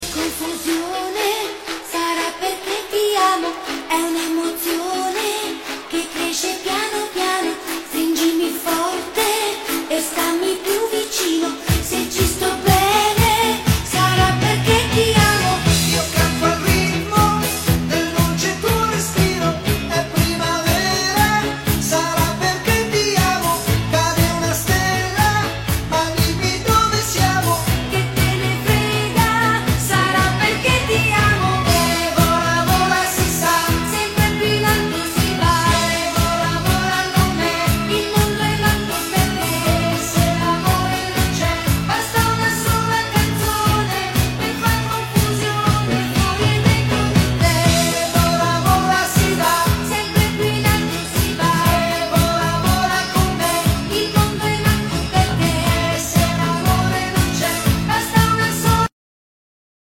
Timeless Italian Pop Hit